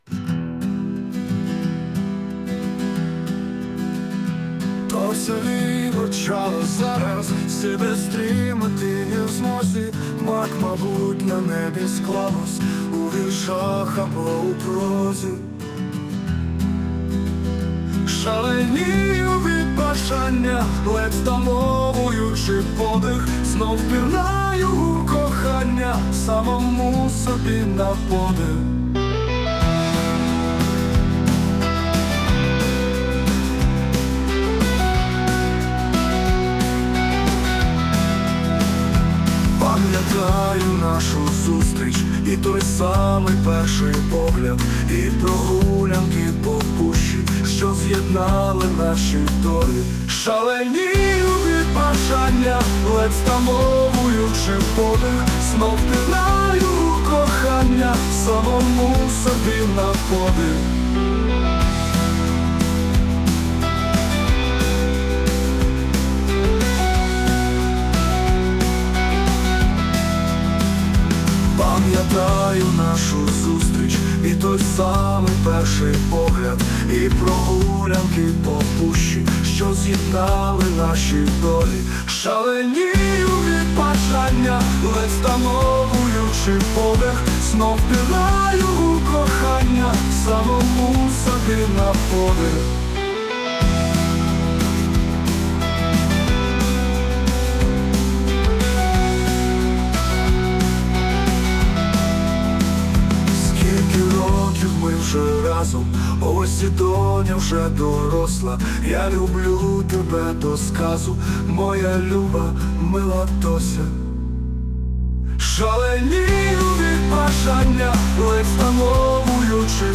Музика та голос =SUNO